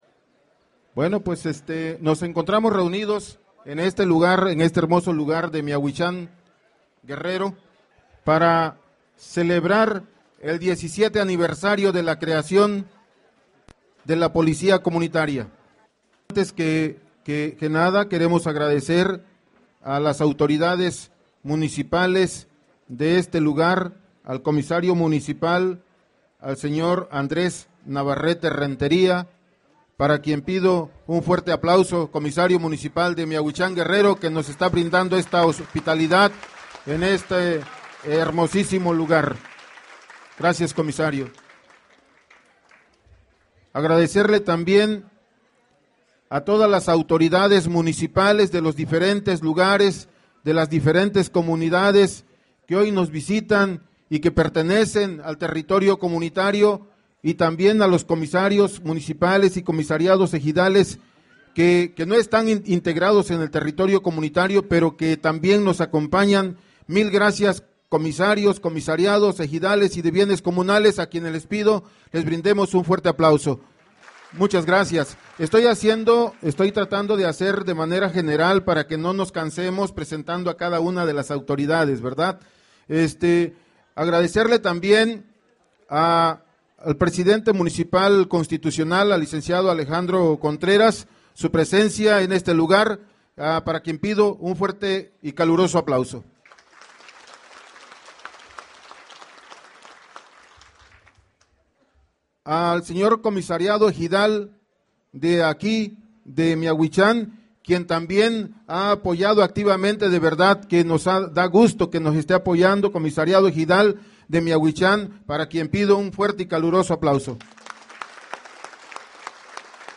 Celebrando los resultados que ha dejado este proyecto que atiende la necesidad de seguridad, la Coordinadora Regional de Autoridades Comunitarias – Policía Comunitaria los días 24 y 25 de Noviembre del año en curso, celebró el XVII aniversario de la creación de la Policía Comunitaria en la comunidad de Miahuichan ubicada en el municipio de San Luis Acatlán, contando con la presencia de diversas organizaciones, colectivos e individuos.
Palabras de bienvenida y agradecimiento en la inauguración:
Inauguracion_del_evento.mp3